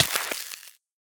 Minecraft Version Minecraft Version snapshot Latest Release | Latest Snapshot snapshot / assets / minecraft / sounds / entity / player / hurt / freeze_hurt2.ogg Compare With Compare With Latest Release | Latest Snapshot
freeze_hurt2.ogg